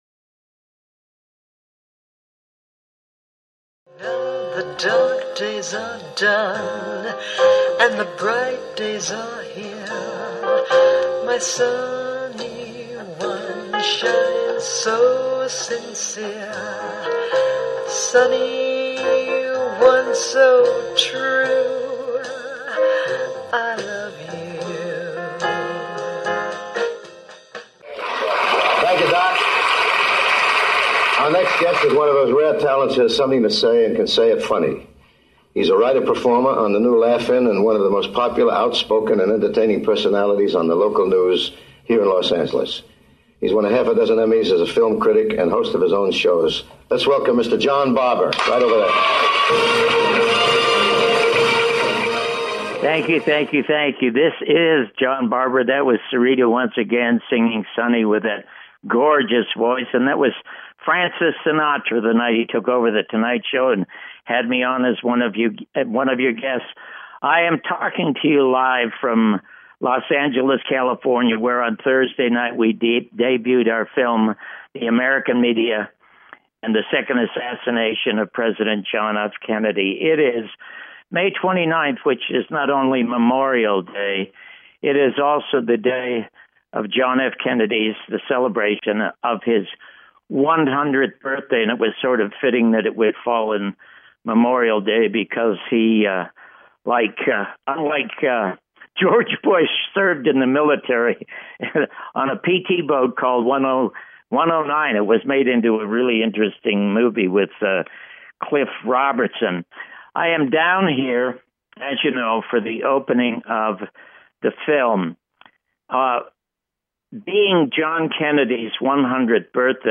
Guest, John B Wells
John Barbours World with John Barbour and special guest John B Wells